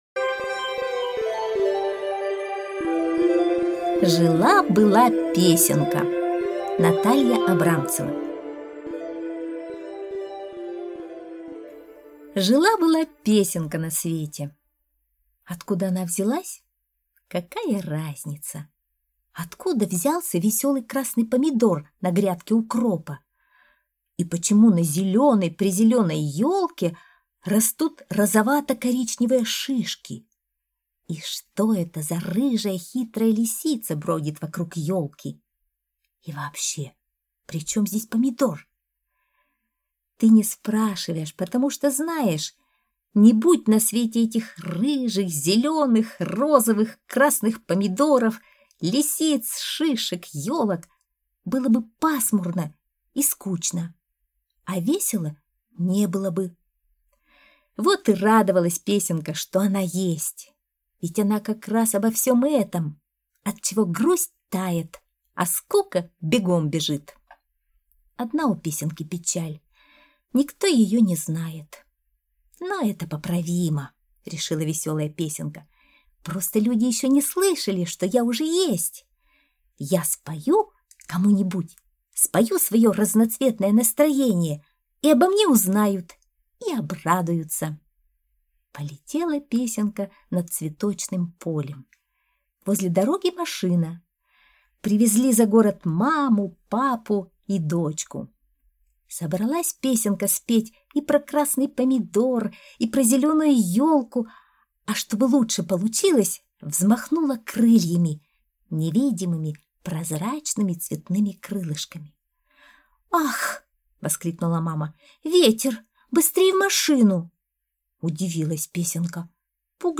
Жила-была песенка - аудиосказка Натальи Абрамцевой - слушать онлайн